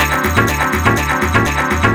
Salsa Martian 1 123-D.wav